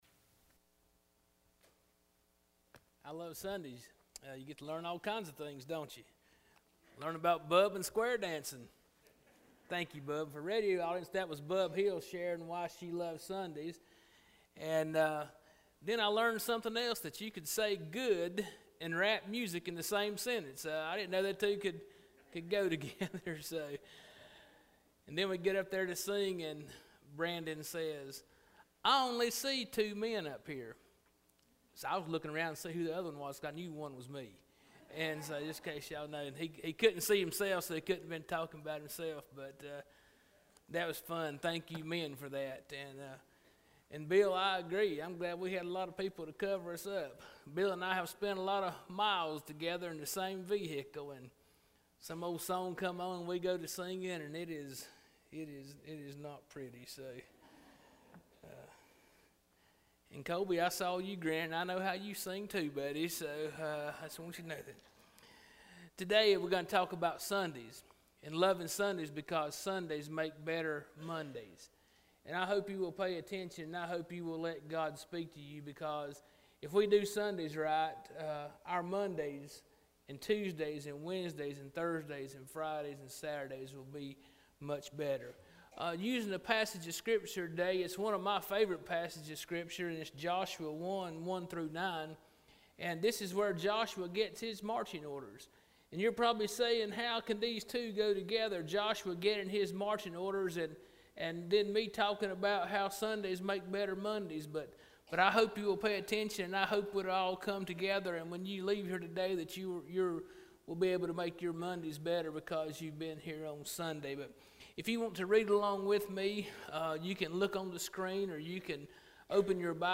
Spencer Baptist Church Sermons